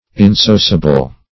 insociable - definition of insociable - synonyms, pronunciation, spelling from Free Dictionary
Insociable \In*so"cia*ble\, a. [L. insociabilis: cf. F.